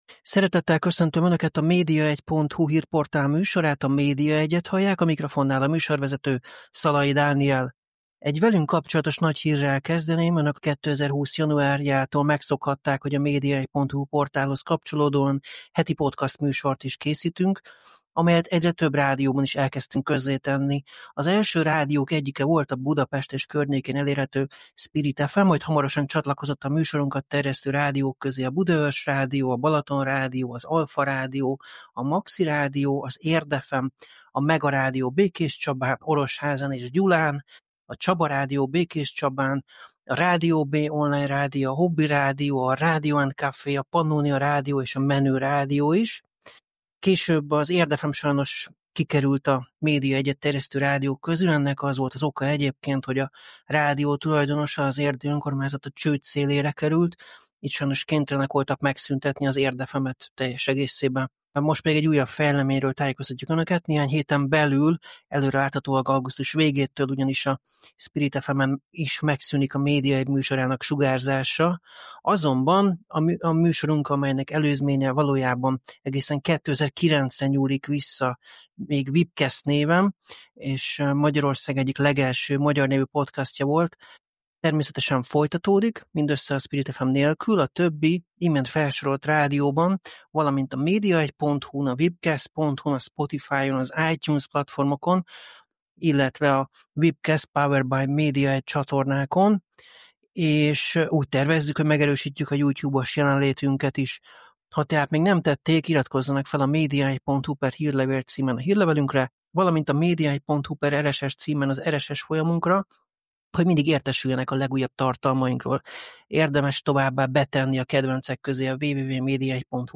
interjúja.